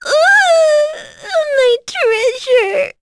Miruru-Vox_Dead.wav